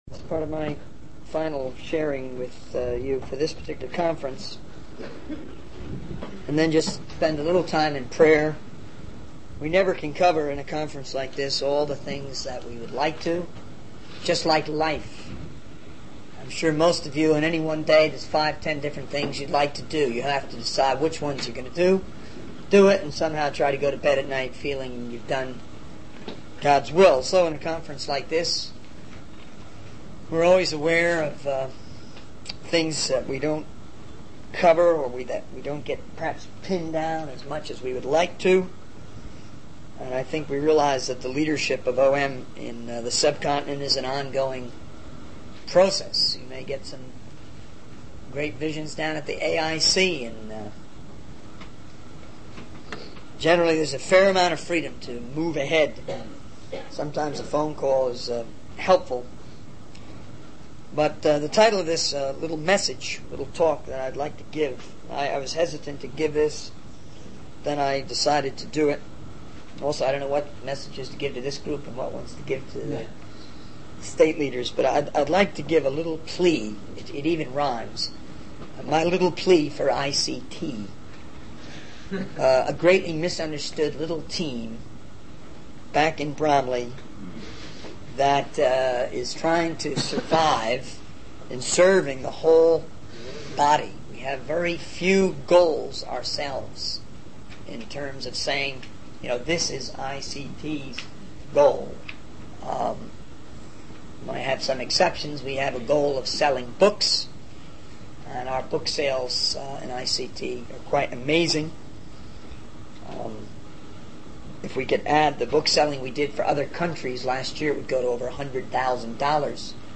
In this sermon, the speaker reflects on the limitations of conferences and the need to prioritize tasks in order to fulfill God's will.